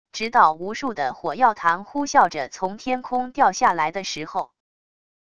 直到无数的火药弹呼啸着从天空掉下来的时候wav音频生成系统WAV Audio Player